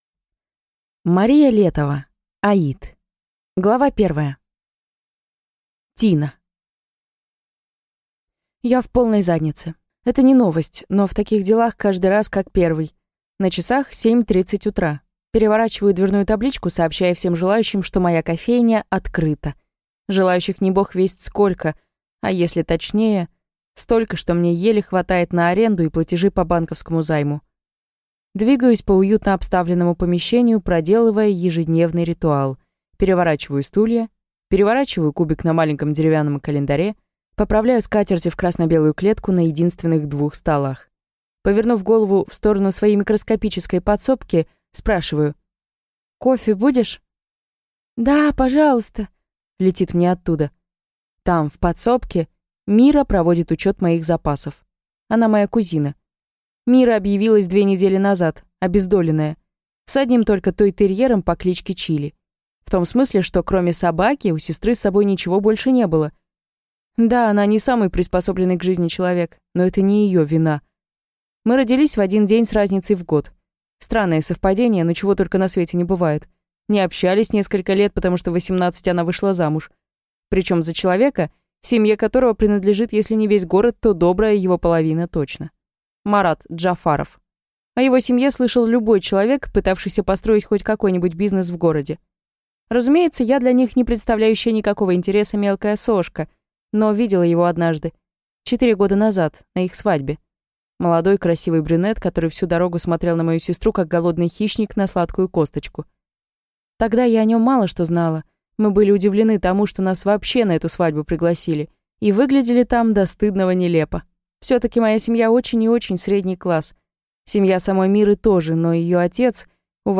Аудиокнига Аид | Библиотека аудиокниг